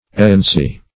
Errancy \Er"ran*cy\, n. [L. errantia.]